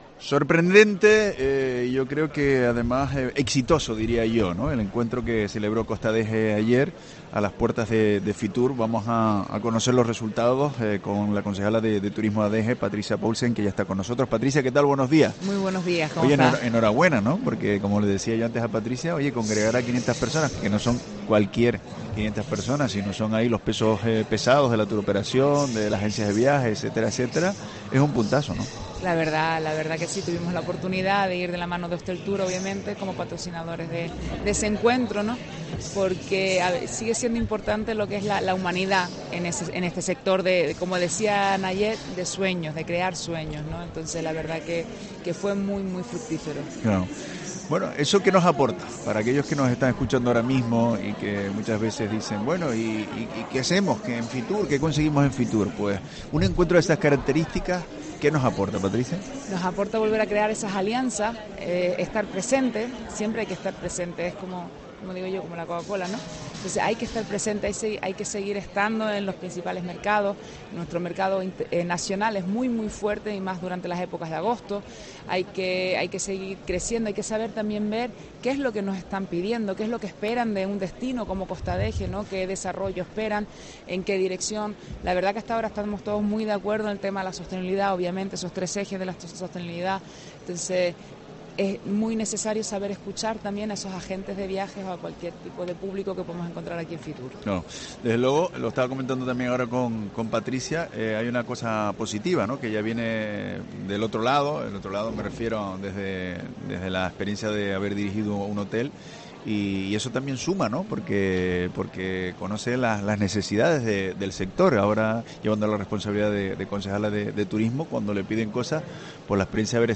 AUDIO: Entrevista a la concejala de Turismo de Adeje, Patricia Paulsen, en FITUR